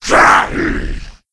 c_saurok_hit1.wav